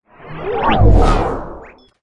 Swoosh 7
Tag: 未来 托管架 无人驾驶飞机 金属制品 金属 过渡 变形 可怕 破坏 背景 游戏 黑暗 电影 上升 恐怖 开口 命中 噪声 转化 科幻 变压器 冲击 移动时 毛刺 woosh 抽象的 气氛